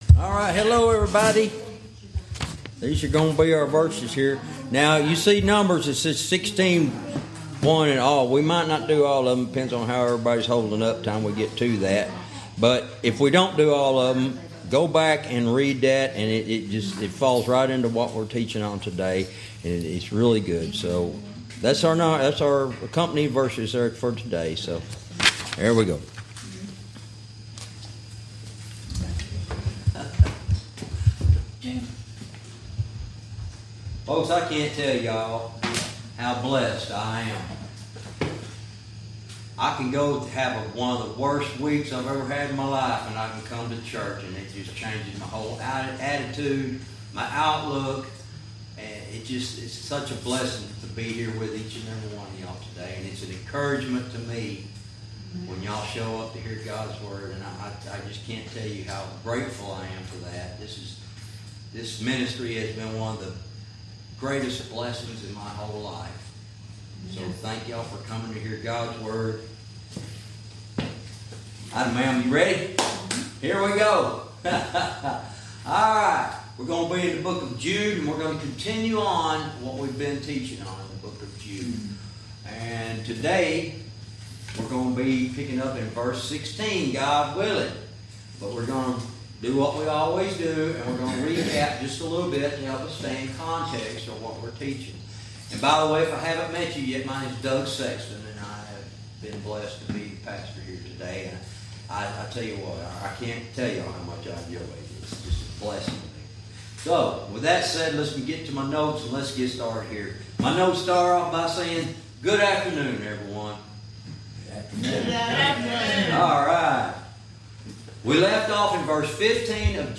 Verse by verse teaching - Jude lesson 70 verse 16